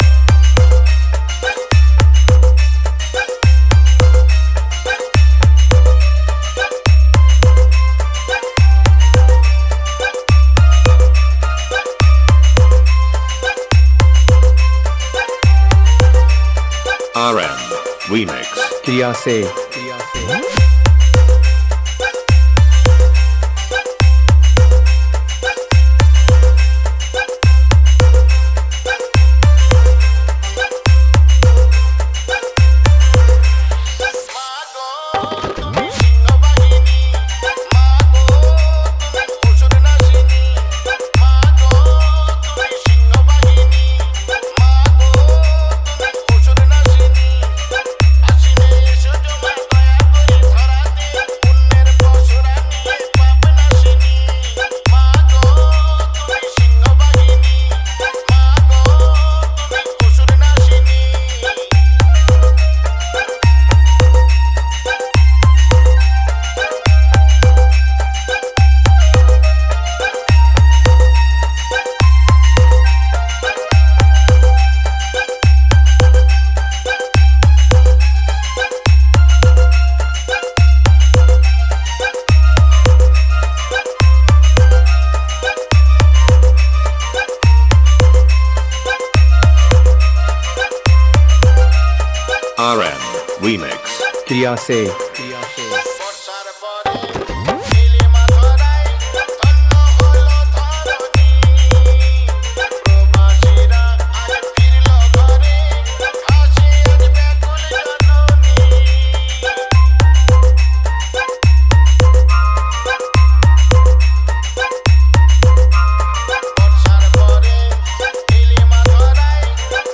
দুর্গ উৎসব স্পেশাল বাংলা নতুন স্টাইল ভক্তি হামবিং মিক্স 2024